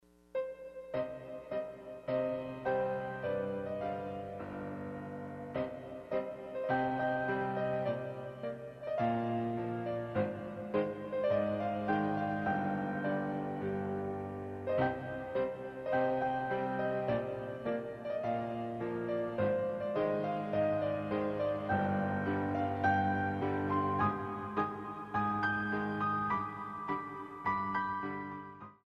33 Piano Selections.